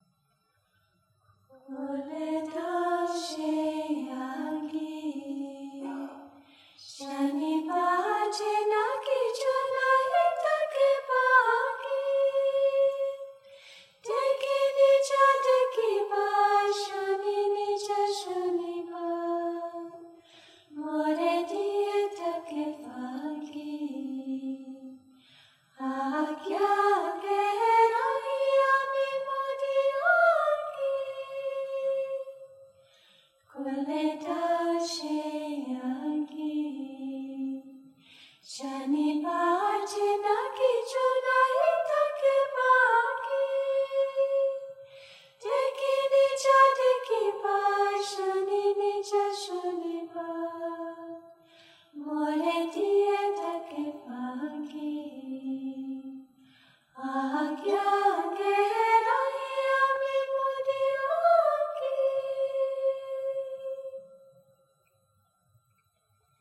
This acapella female international choir has regularly performed since 1987 at many concerts or meditative festivals. Their simple yet soulful interpretations are a good introduction to Sri Chinmoy’s meditative melodies. This performance was recorded during a visit to Bali in 2015.